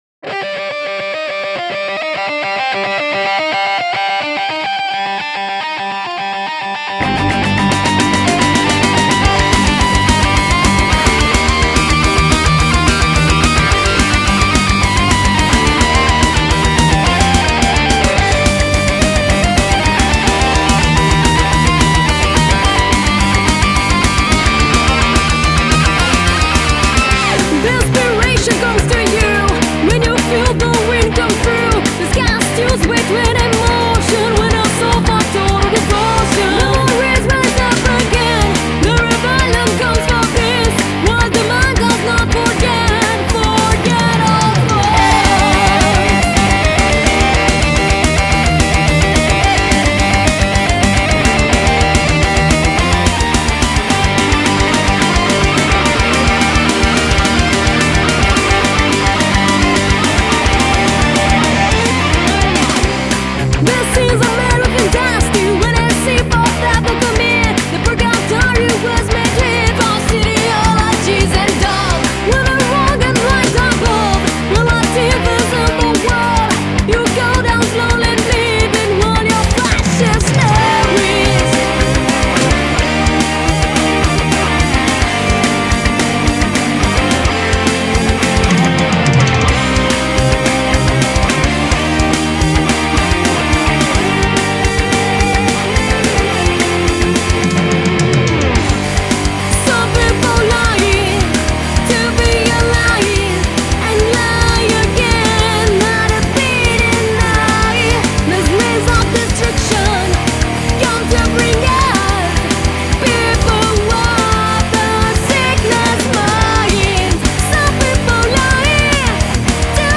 EstiloHeavy Metal